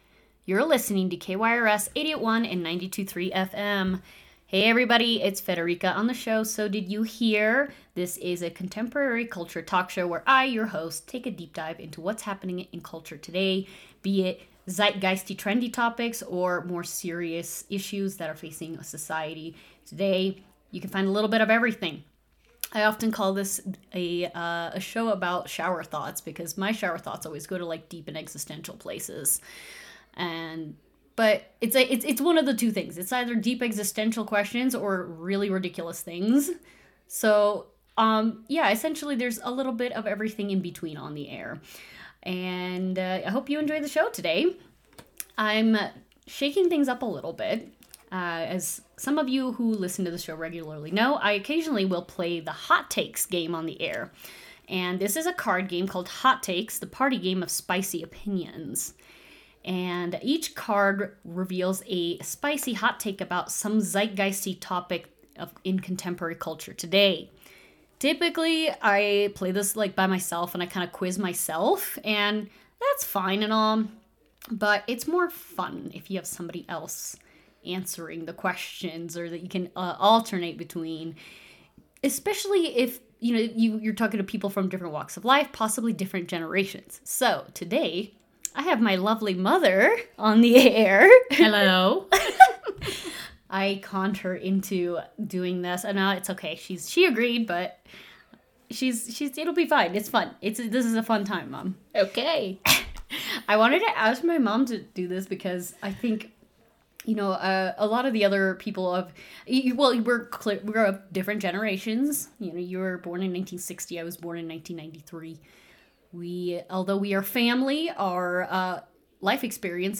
What happens when you get a boomer and a millennial to debate over “hot takes”? It’s a common stereotype that these two generations can’t agree on anything, but how true is that really?